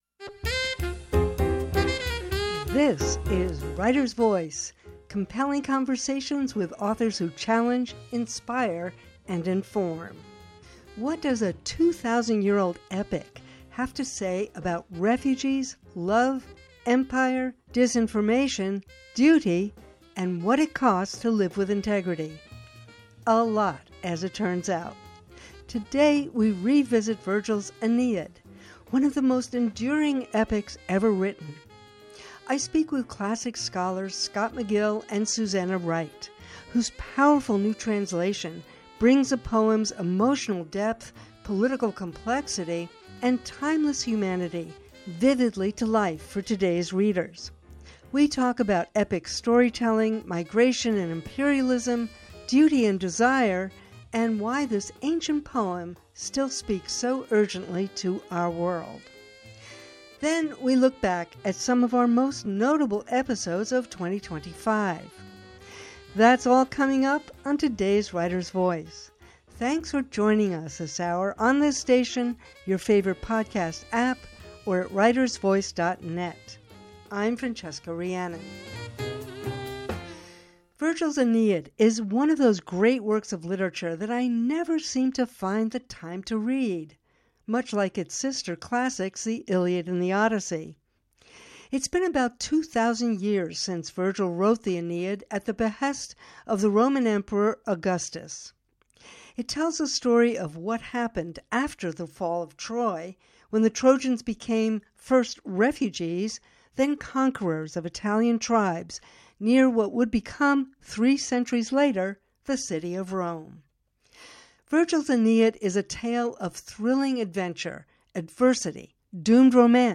Writer’s Voice: compelling conversations with authors who challenge, inspire, and inform. What does a 2,000-year-old epic have to say to us today about exile, duty, love, power, war, misinformation, and the fragile hopes of human community?